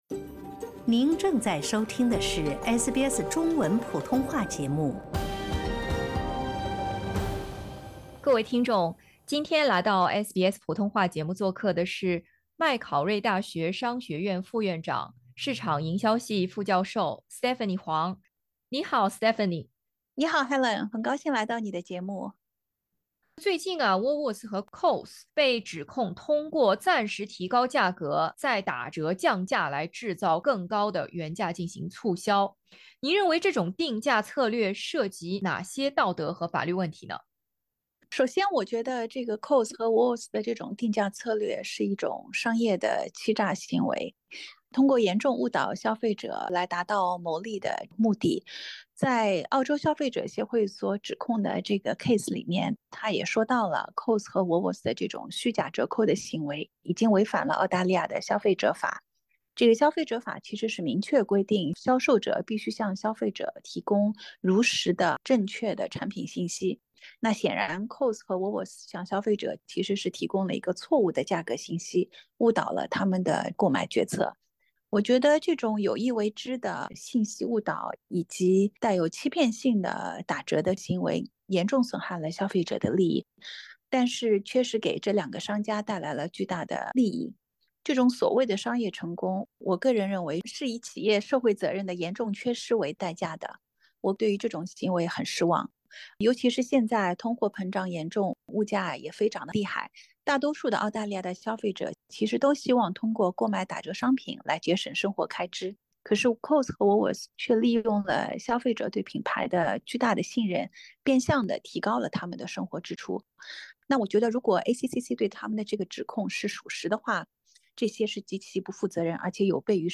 澳大利亚竞争与消费者委员会（ACCC）已在联邦法院对Woolworths和Coles分别提起诉讼，指控它们因在数百种超市常见商品上做虚假的打折定价宣传来误导消费者而违反了《澳大利亚消费者法》。请点击音频，收听采访！